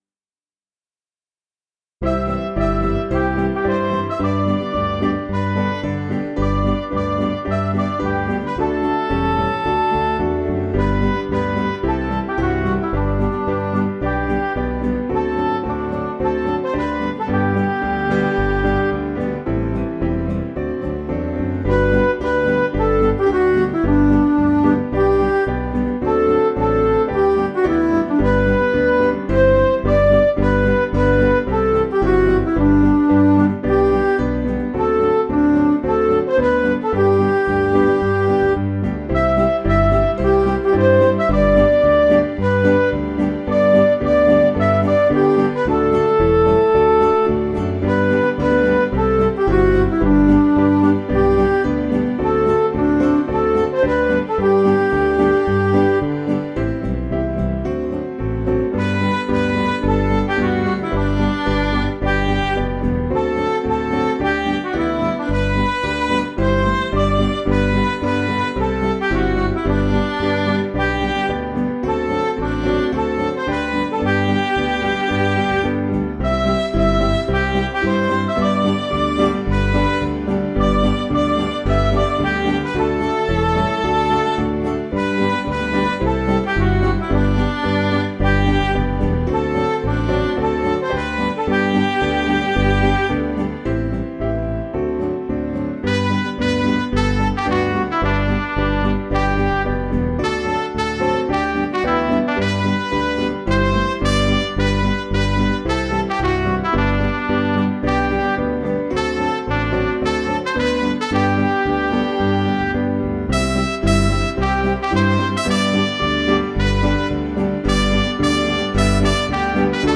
Piano & Instrumental
(CM)   3/G
Midi